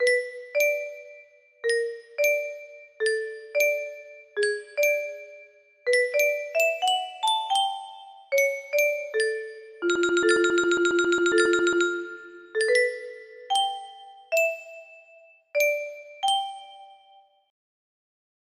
IM BACK BABY music box melody